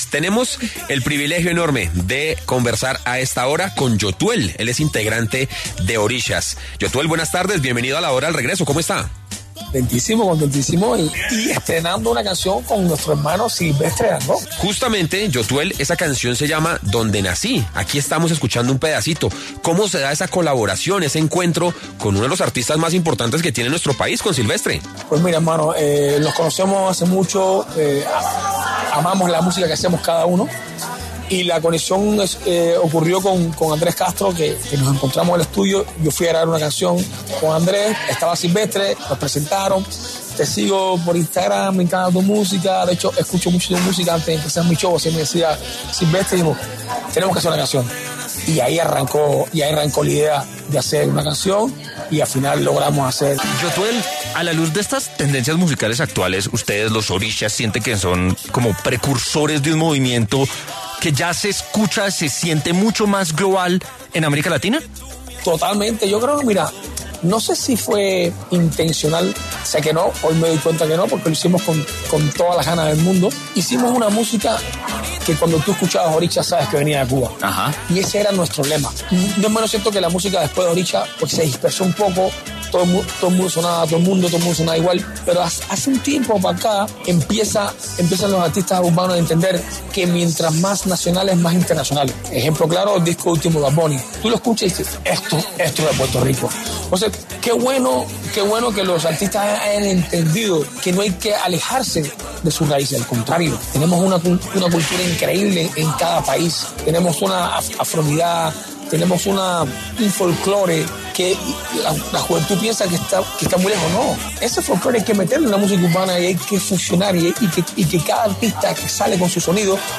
Yotuel, vocalista de la agrupación cubana Orishas, habló en los microfonos de La Hora del Regreso de W Radio sobre su nuevo tema ‘Donde Nací’, una colaboración con el colombiano Silvestre Dangond.